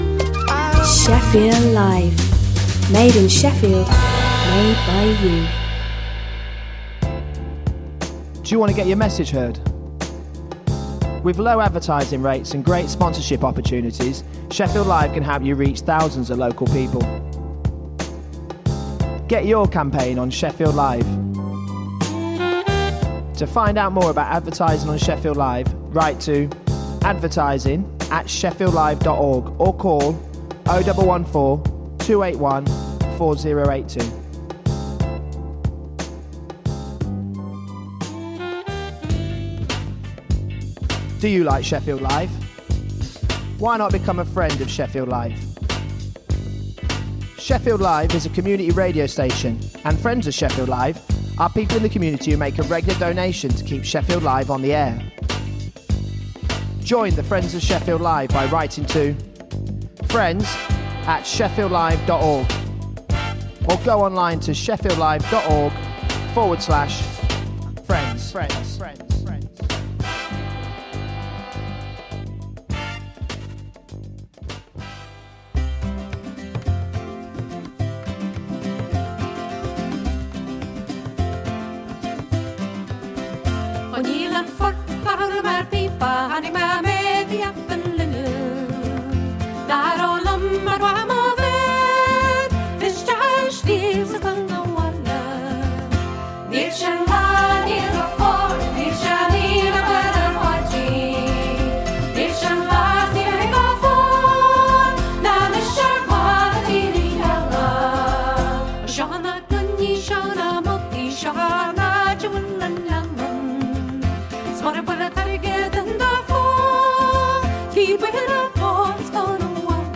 Business news debate and interviews for anyone interested in growing or starting a business